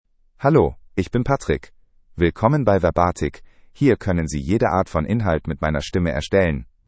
MaleGerman (Germany)
PatrickMale German AI voice
Patrick is a male AI voice for German (Germany).
Voice sample